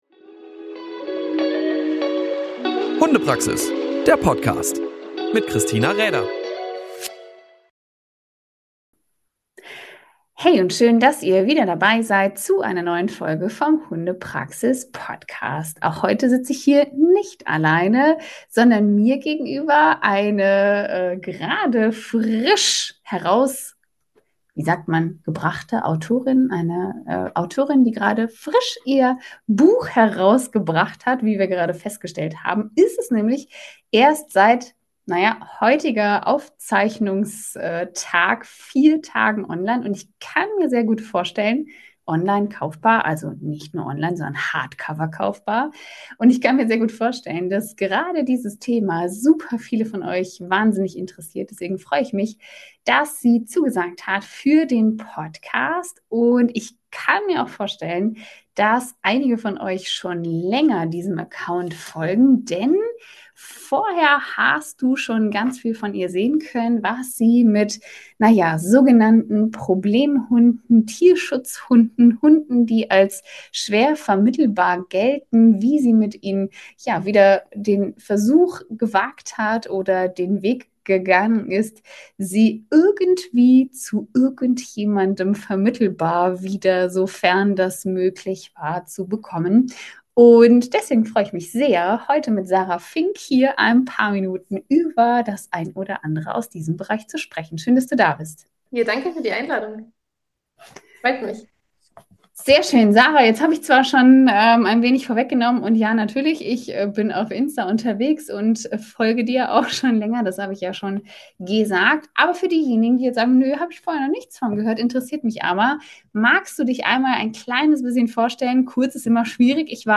In der Folge unterhalte ich mich mit ihr über ihre Erkenntnisse und was sie auch für unser Wissen als Hundehalter für wichtige Sichtweisen mitbringen.